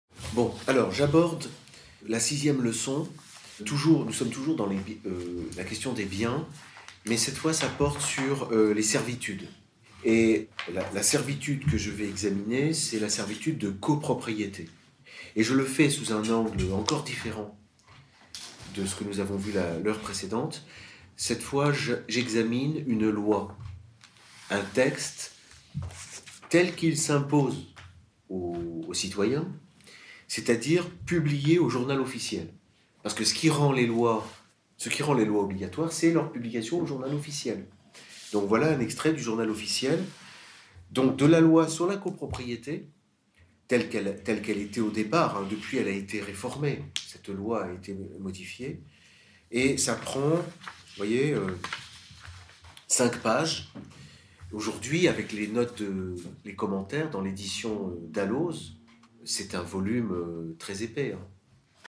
Le séminaire « l’’immeuble en copropriété » dure une heure, c’est le live d’un cours de droit que j’ai délivré dans le cadre des Formations d’Egalité et Réconciliation.